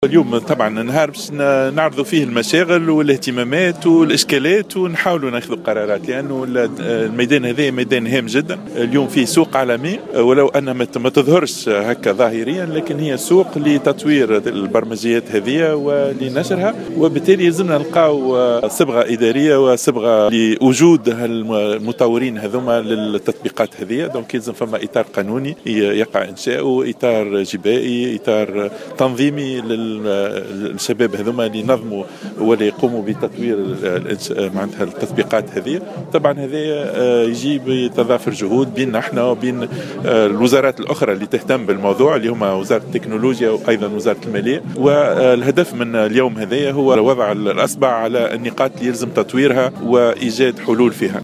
قال وزير التعليم العالي والبحث العلمي شهاب بودن خلال إشرافه اليوم الأربعاء 16 مارس 2016 على تظاهرة علمية بالمعهد العالي لفنون الملتميديا بالمركب الجامعي بمنوبة إنه من الضروري إنشاء إطار قانوني وجبائي للتطبيقات التكنولوجية والبرمجيات التي يطورها الشباب وذلك بالتنسيق والتعاون مع وزارتي تكونولوجيا الاتصال والمالية.